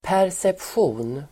Ladda ner uttalet
Folkets service: perception perception substantiv, perception Uttal: [pärsepsj'o:n] Böjningar: perceptionen, perceptioner Synonymer: uppfattning Definition: det som sinnena uppfattar, varseblivning, förnimmelse